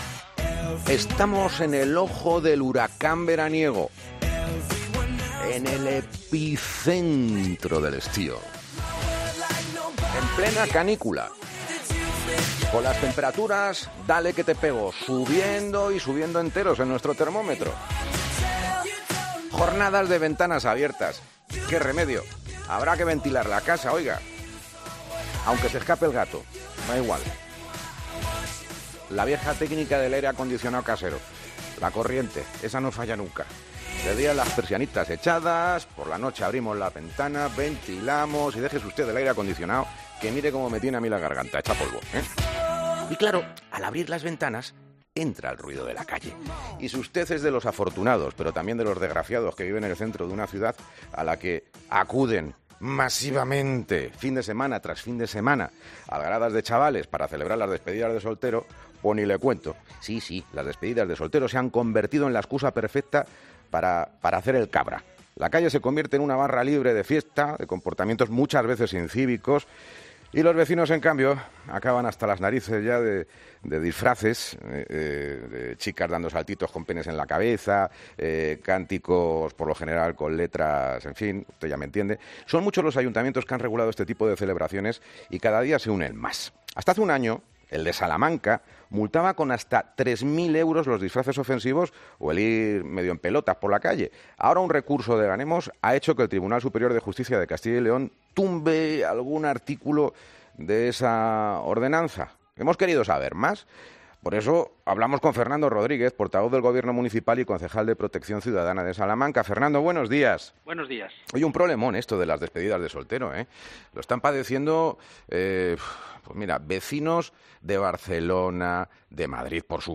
Fernando Rodríguez, portavoz del Gobierno Municipal y Concejal de Seguridad Ciudadana de Salamanca